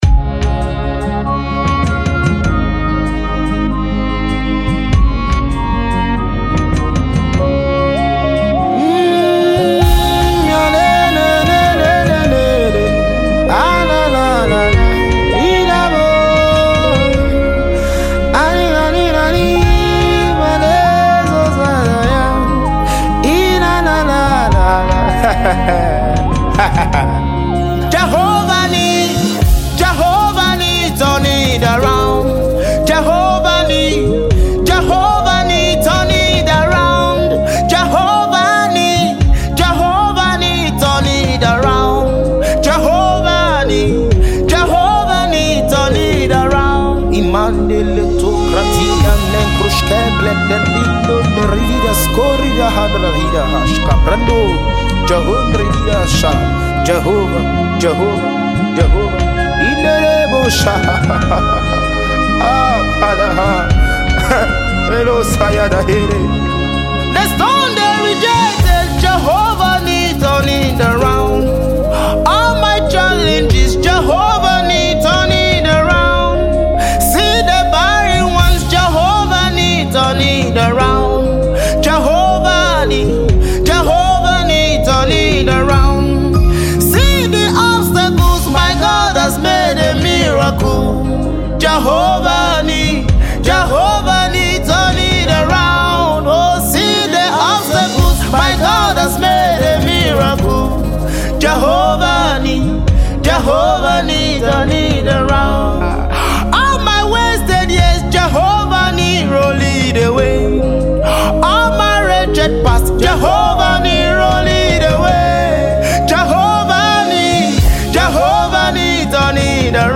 Nigerian Gospel music minister
a song full of grace and hope.